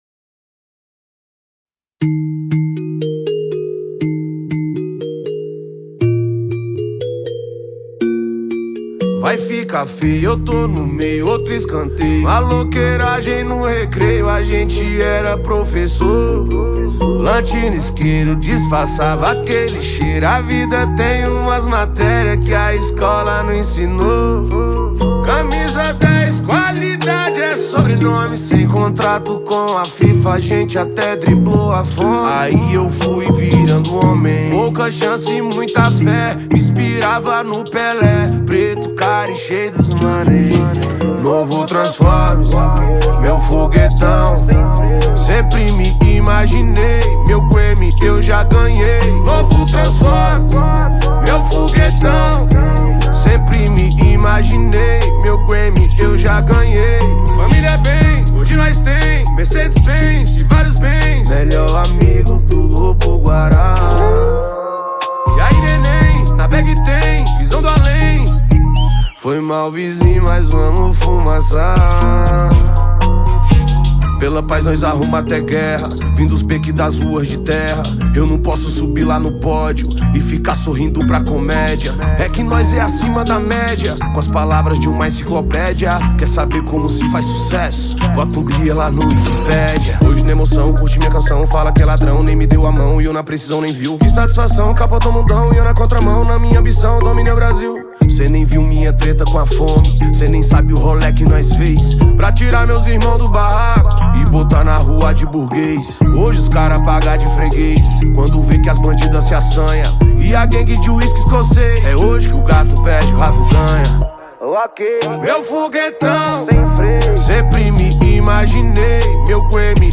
2025-02-24 21:51:13 Gênero: Hip Hop Views